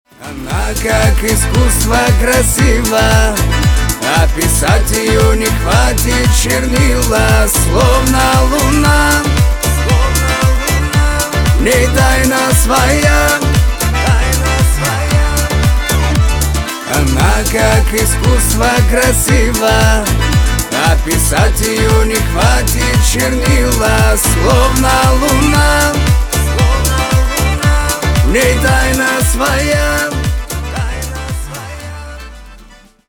на русском восточные на девушку про любовь